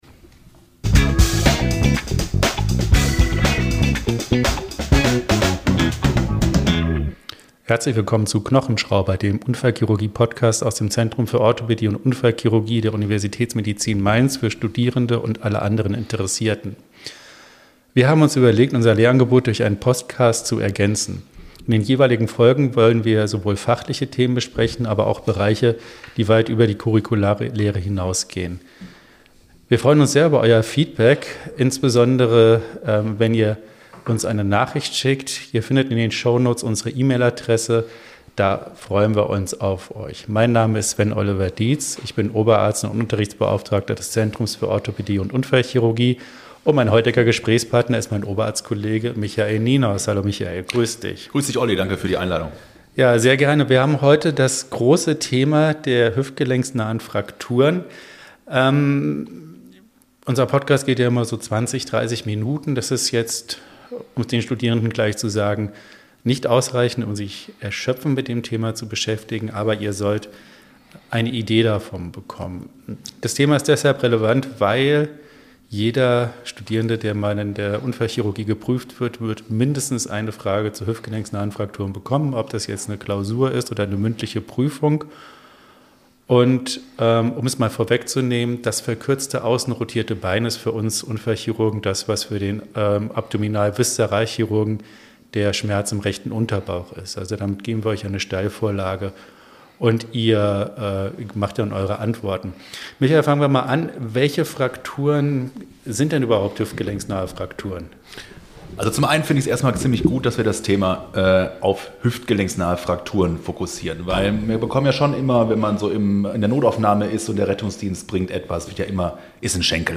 Zusammen mit seinen Interviewgästen nimmt er Euch mit in den klinischen Alltag am ZOU, bespricht mit ihnen praxisnah relevante Krankheitsbilder, diagnostische und therapeutische Prinzipien sowie typische Fallbeispiele – fundiert, verständlich und auf Augenhöhe.